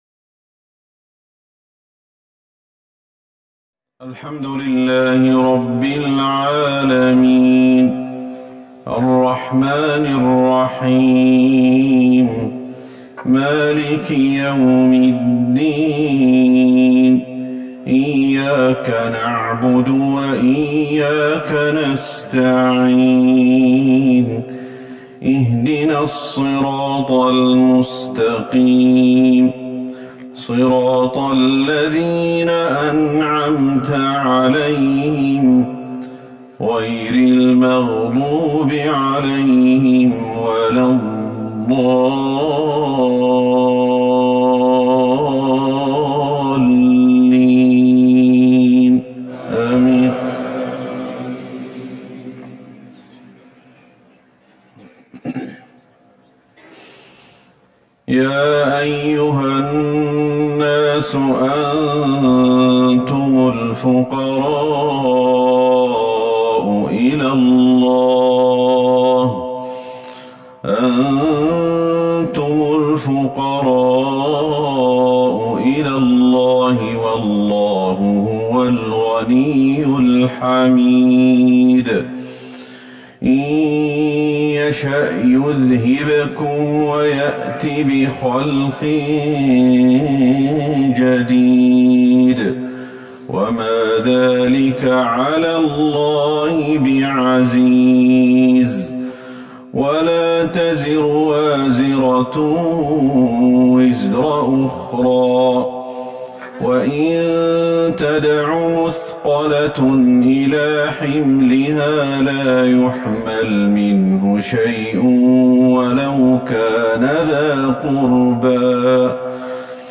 صلاة العشاء ١٧ جمادي الاولى ١٤٤١هـ سورة فاطر Evening prayer, 5-1-2020 from Surah Fatir > 1441 🕌 > الفروض - تلاوات الحرمين